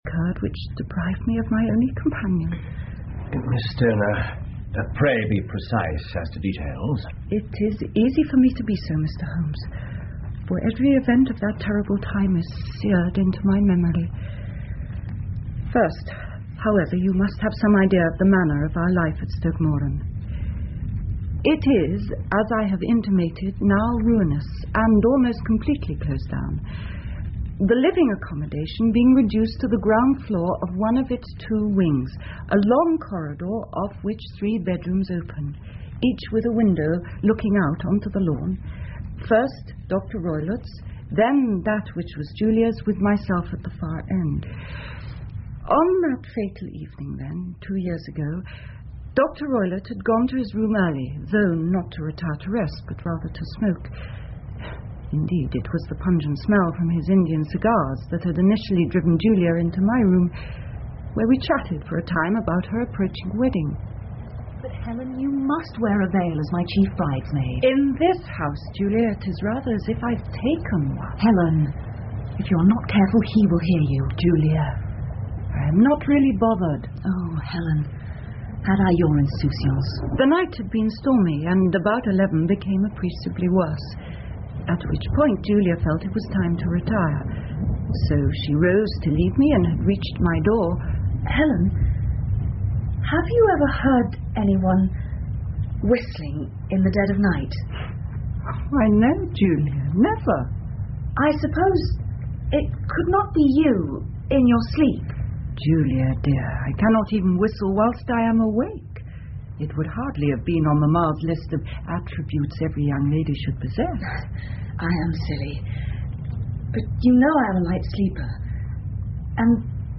福尔摩斯广播剧 The Speckled Band 3 听力文件下载—在线英语听力室